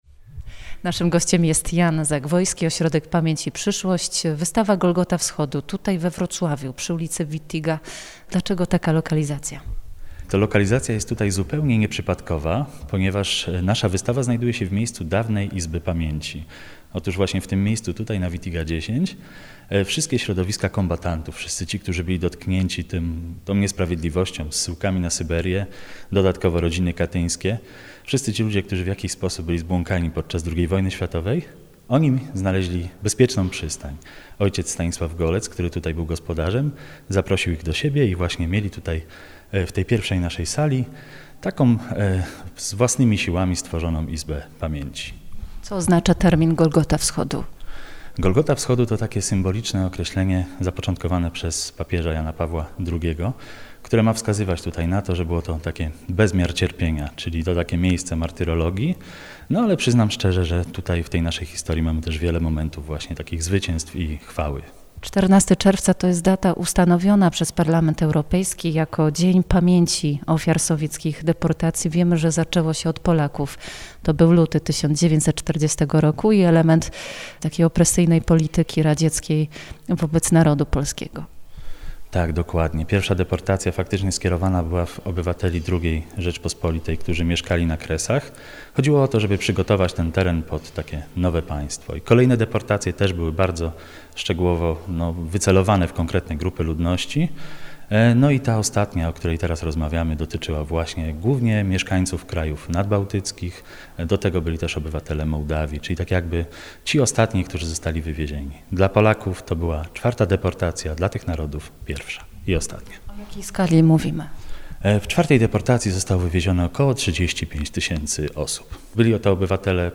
Rozmowa o przesiedleniach i powrotach w piątek 14 czerwca po godz. 14:00.